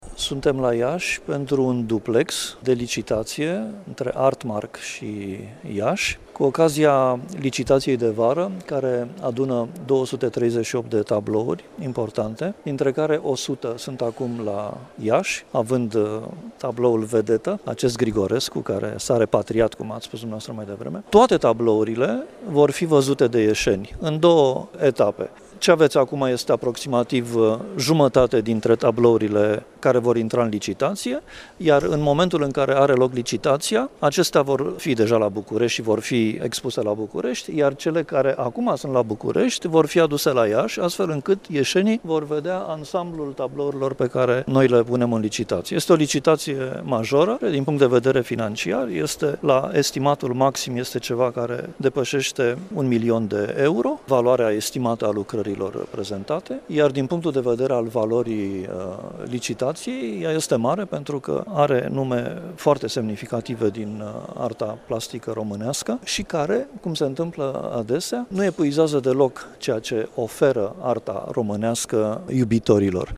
Directorul Casei care organizează licitaţia, Horia Roman Patapievici, a declarat că în total, în România, au sosit 240 de tablouri.